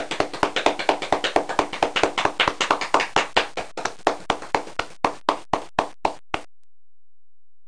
00246_Sound_2CLAPERS.WAV